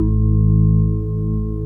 B3 ROCKG#1.wav